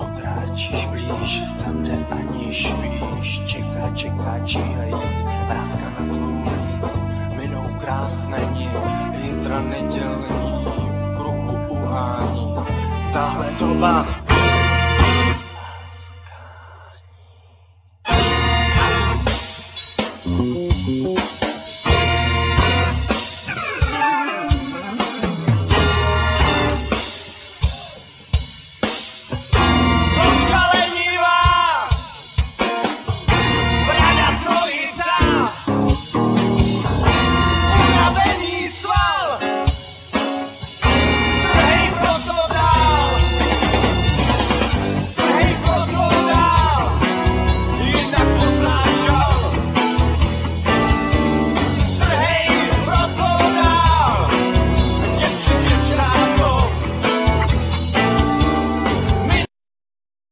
Vocals,Guitar
Piano
Bass,Vocal,Flute
Trumpet
Drums,Vocals